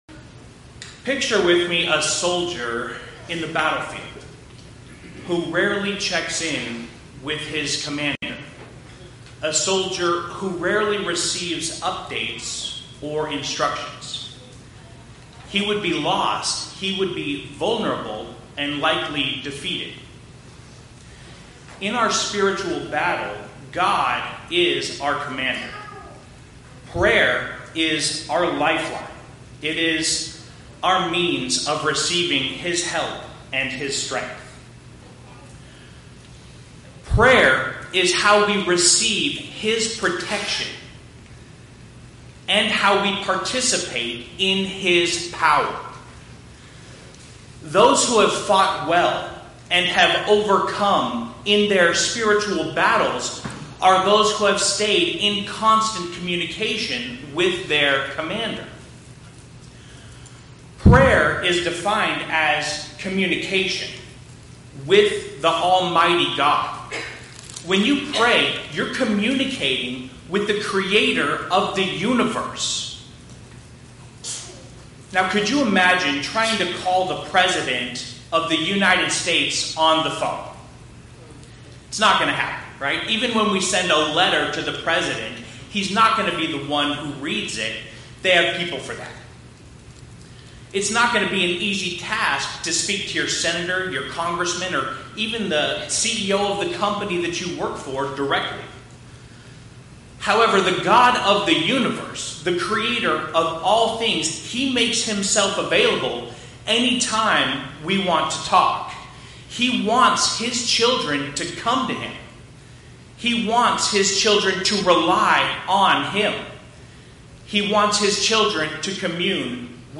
Podcasts Videos Series Sermons God's Power